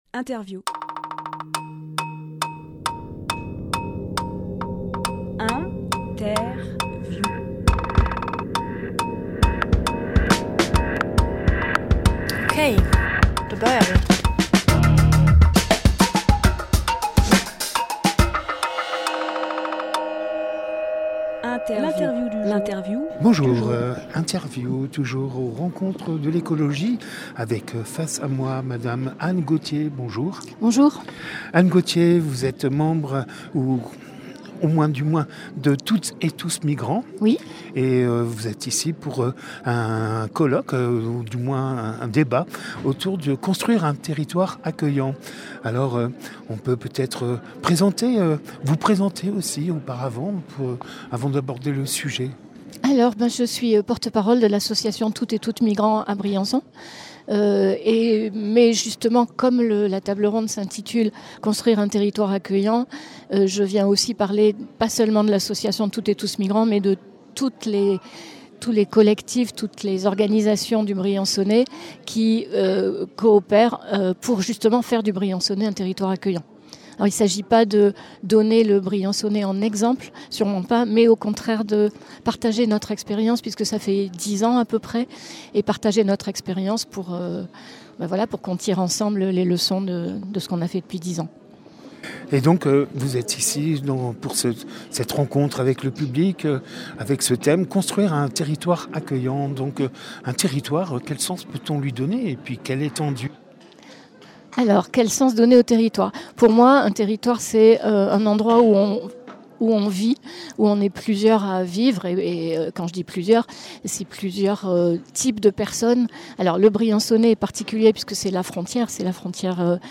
Interview
lieu : Salle Polyvalente